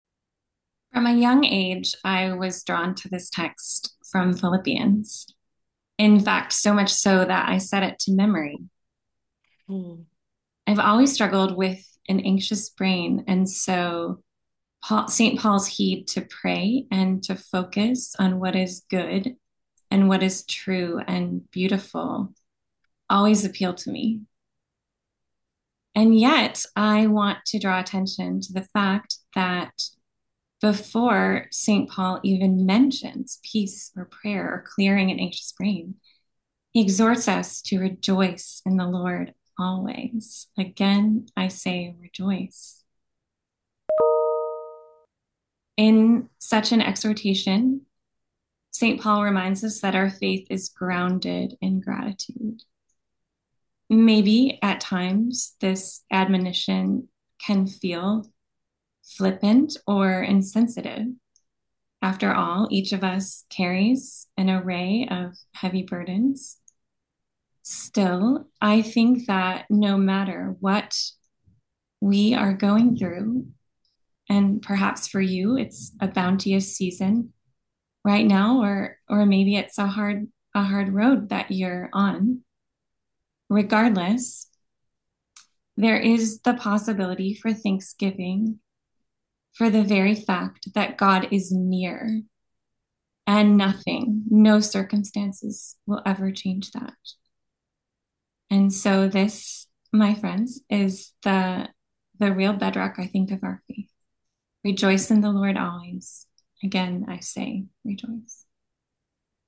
New Year’s Thanksgiving Prayer Service & Fellowship Zoom Event Reflections